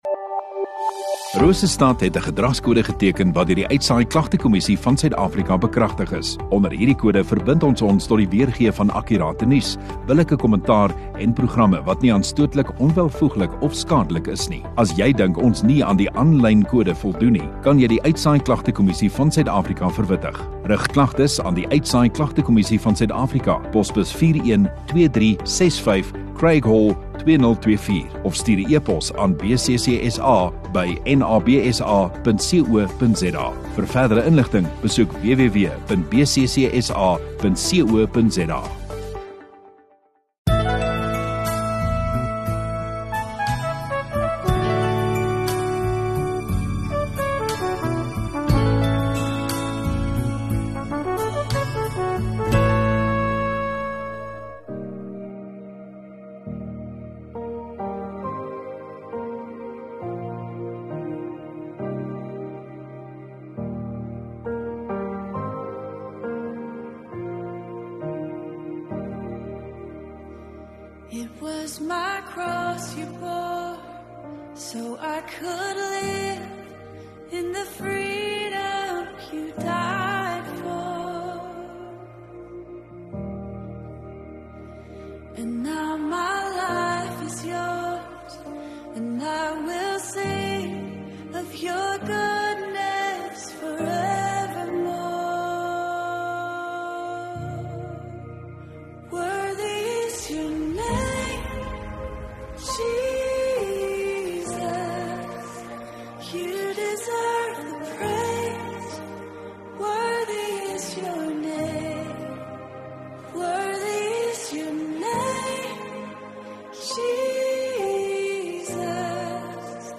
3 Jan Vrydag Oggenddiens